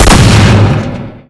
schrabidiumShoot.ogg